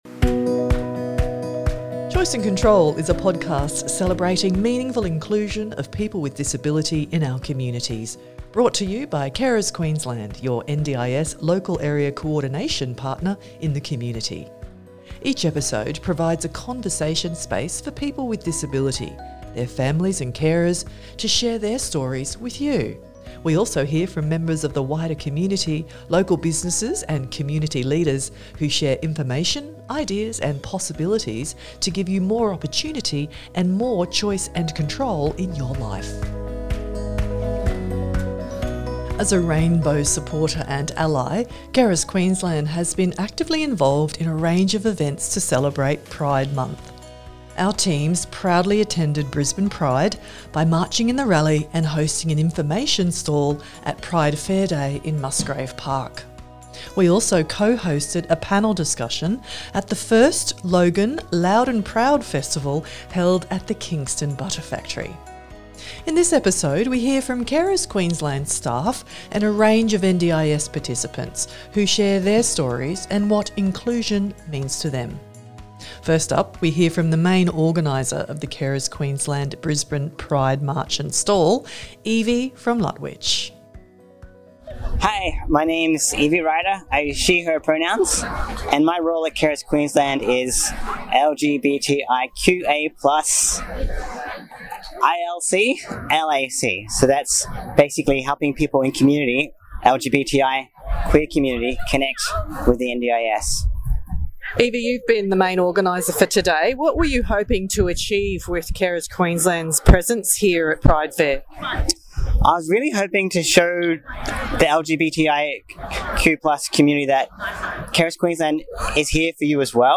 Our teams proudly attended Brisbane Pride by marching in the rally and hosting an information stall at Pride Fair Day in Musgrave Park.
In this episode we hear from Carers Queensland staff and a range of NDIS participants who share their stories and what inclusion means to them.